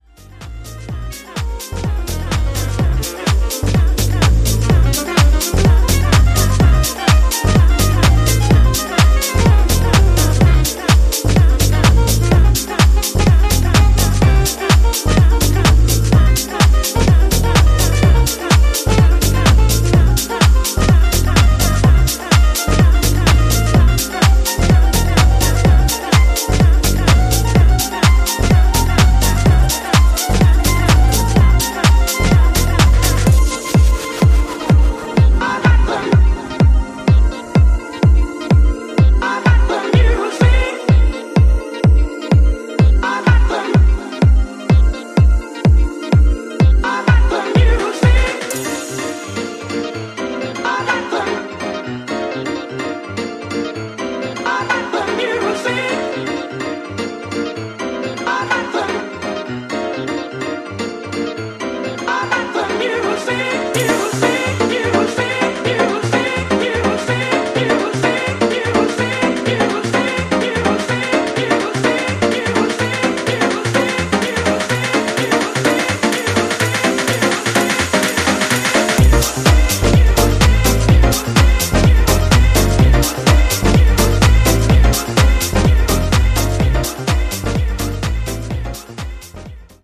both driven by irresistible samples and undeniable grooves.
upfront and built for the dancefloor